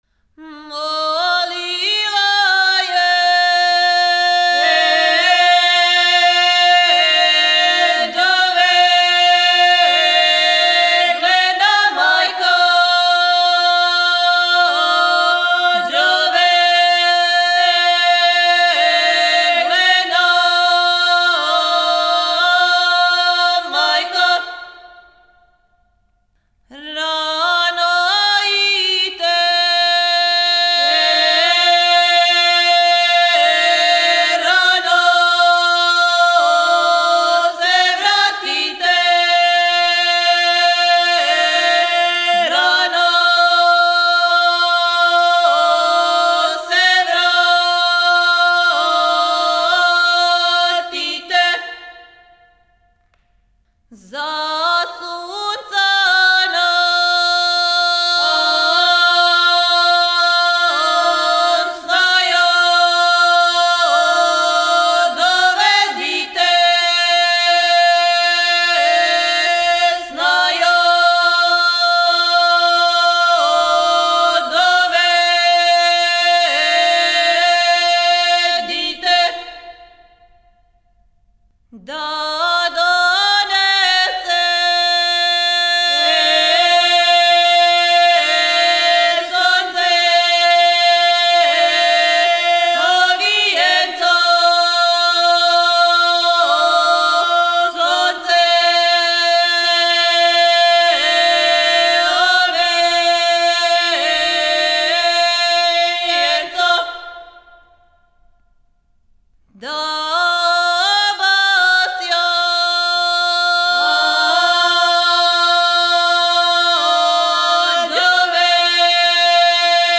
Снимци Етномузиколошки одсек Музичке школе "Мокрањац", Београд (4.3 MB, mp3) (Песма је научена на основу снимака групе Црнућанка.)
Порекло песме: Село Горња Црнућа под Рудником Начин певања: Кратки сватовки глас